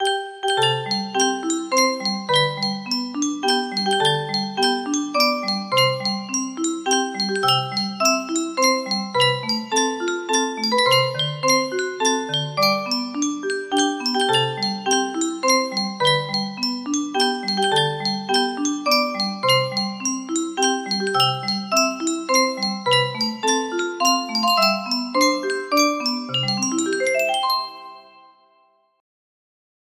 Happy Birthday To K music box melody